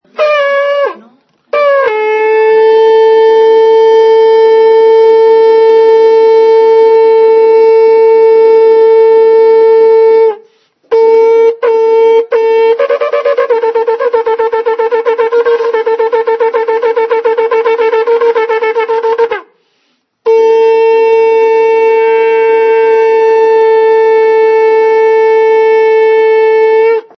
554 sonido shofar 4 elul 5772